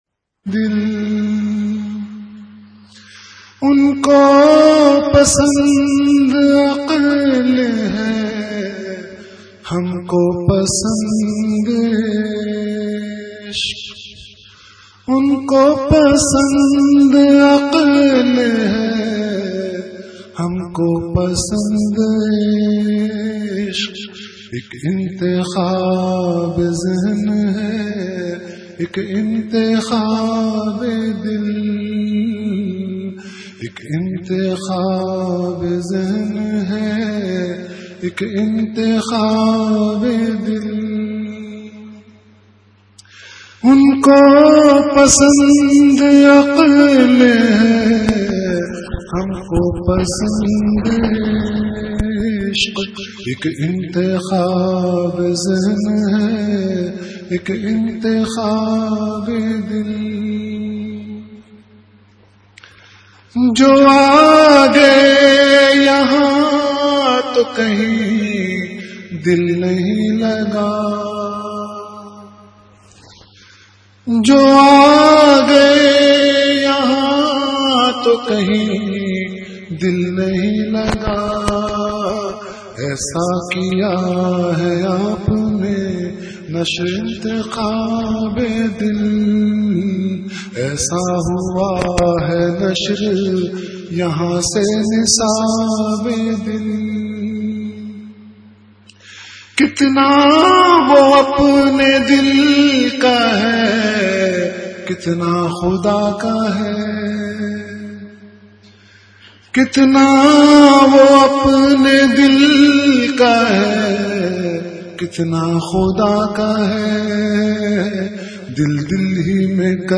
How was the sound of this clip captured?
Delivered at Home.